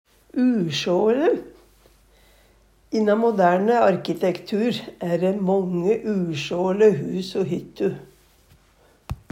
Høyr på uttala Ordklasse: Adjektiv Attende til søk